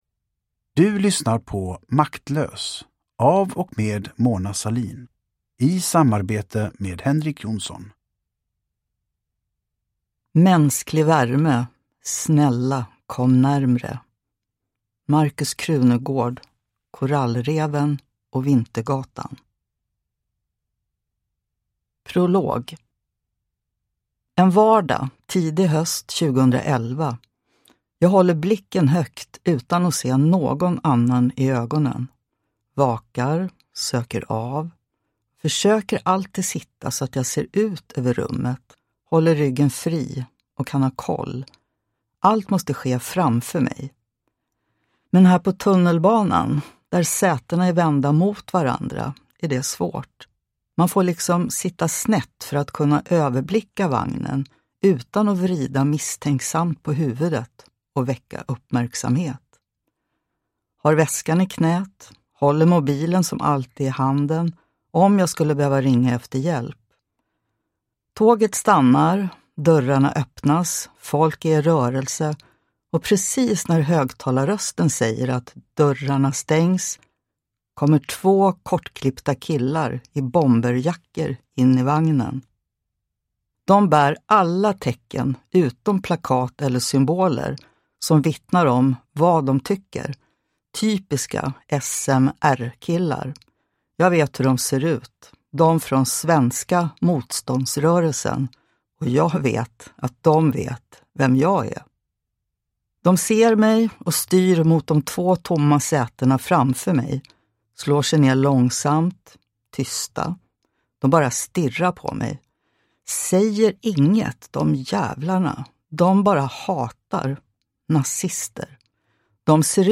Uppläsare: Mona Sahlin
Ljudbok